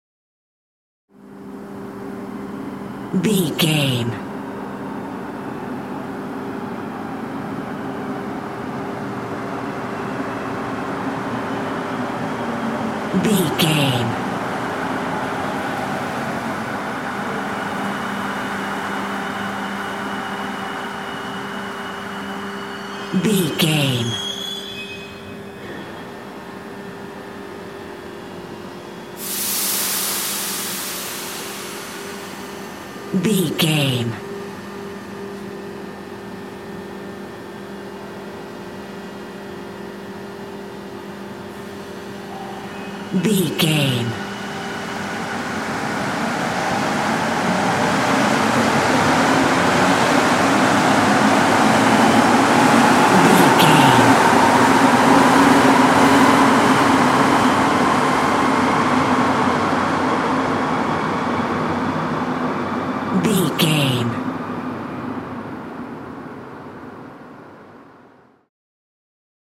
Subway train arrive leave station
Sound Effects
urban
ambience